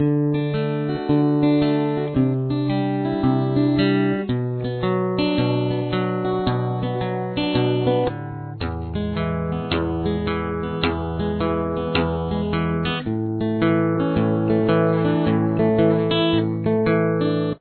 Verse